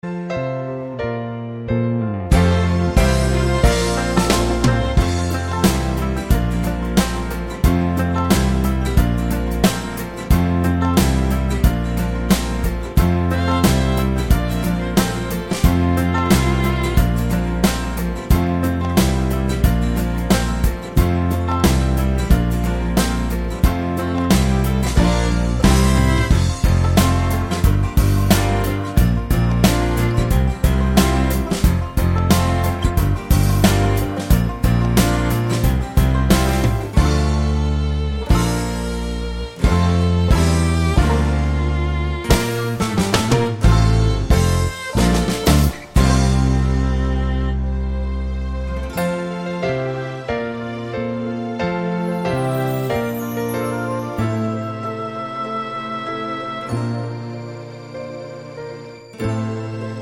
Soundtracks